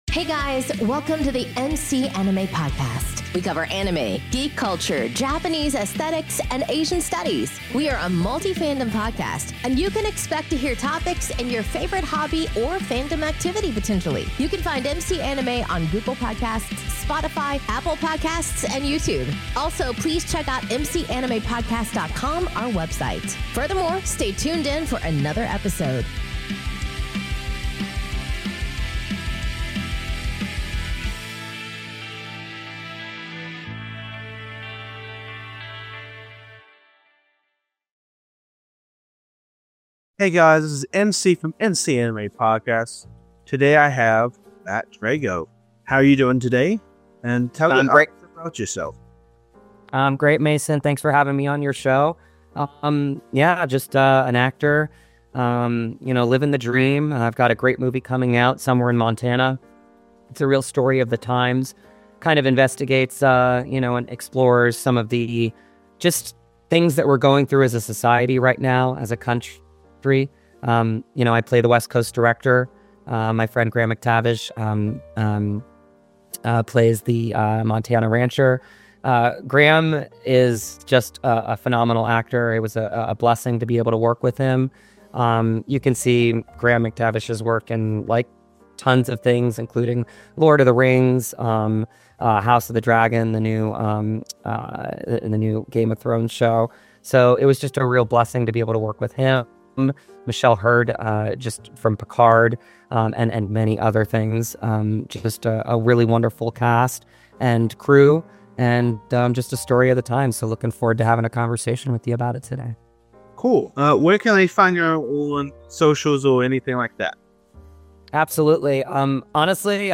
Welcome to our fandom-centric podcast, where we explore anime, geek culture, and dive deep into Japanese aesthetics and Asian Studies. Our family-friendly program features open forum discussions with a conversational, casual tone, offering unique perspectives on the topics that matter most.